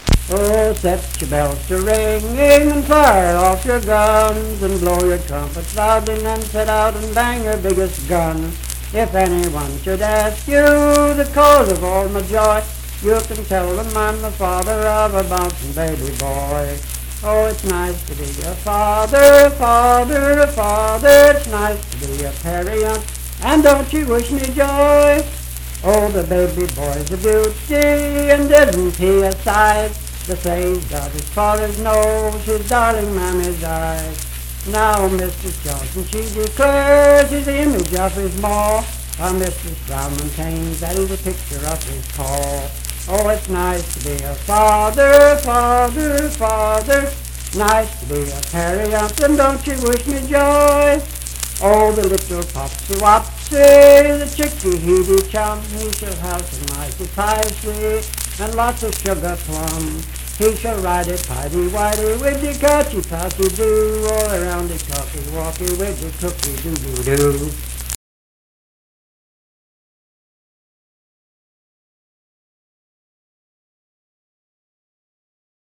Unaccompanied vocal performance
Humor and Nonsense, Children's Songs
Voice (sung)
Harrison County (W. Va.)